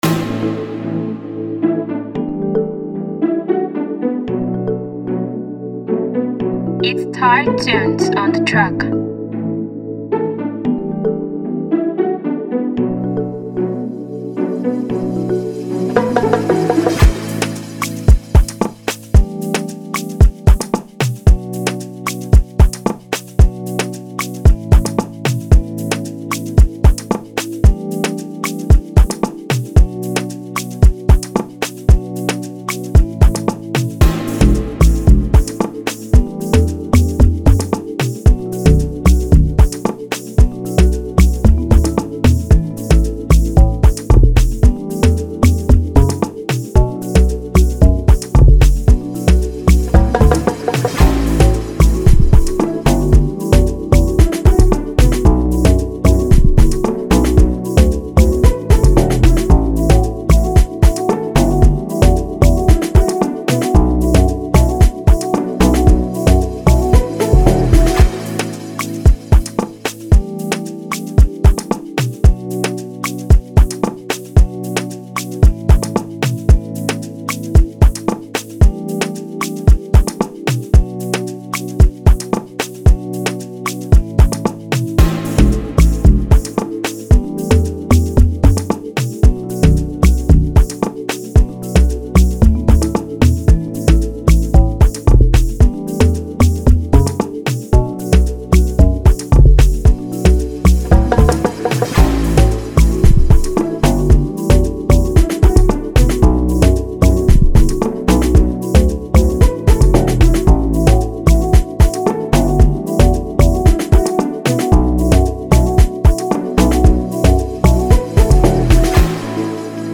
Amapiano instrumental
” reminiscent of the cool South African vibes.
With its infectious rhythms and melodic layers